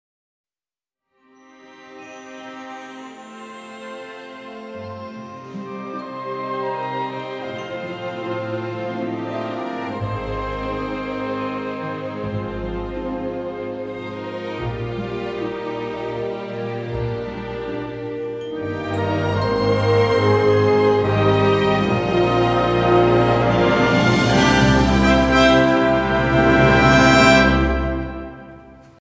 Звук заставки мультфильмов